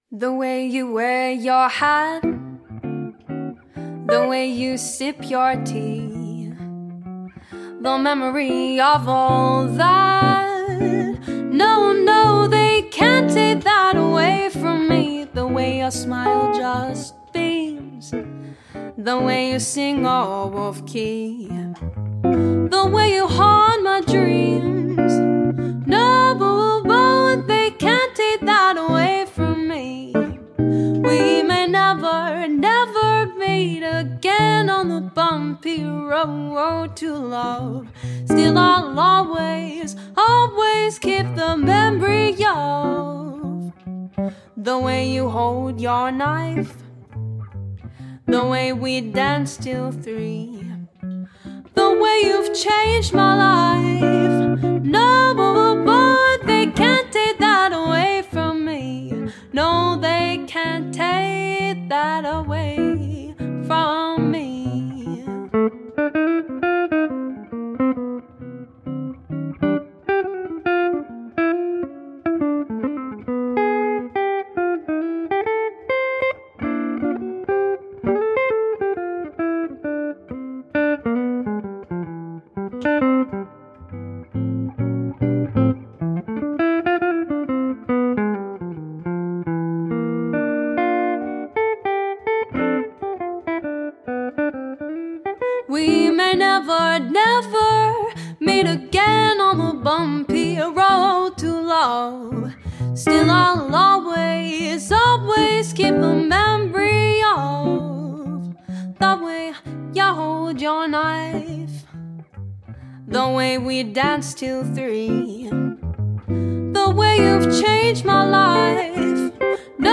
• Enchanting, silky-smooth vocals
• Masterful jazz guitar with warmth and finesse
• Jazz Standards
Female Vocals, Guitar